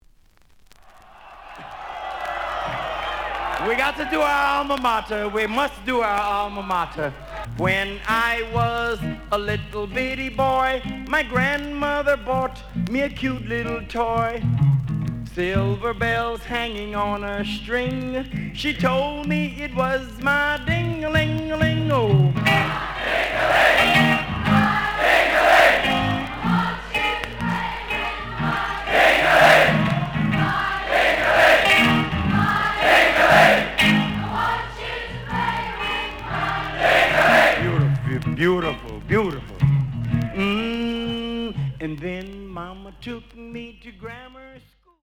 The audio sample is recorded from the actual item.
●Genre: Rhythm And Blues / Rock 'n' Roll
Some periodic noise on last of A side.